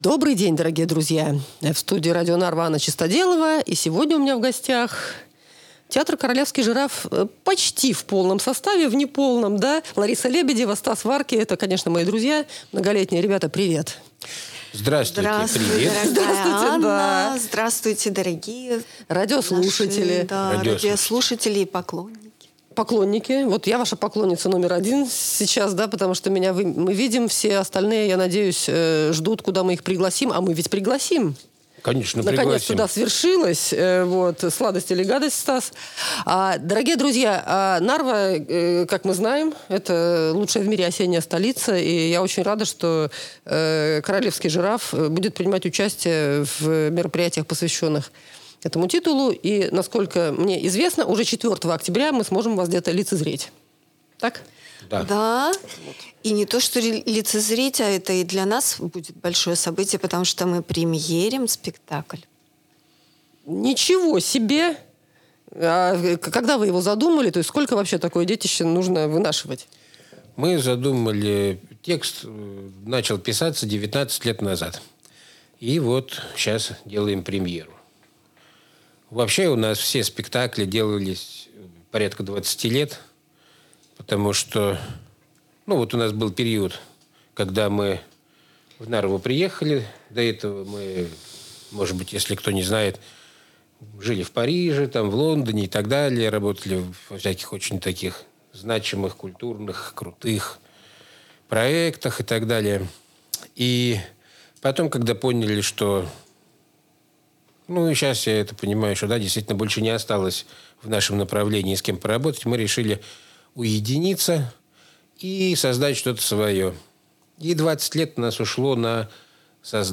Подробности - в интервью.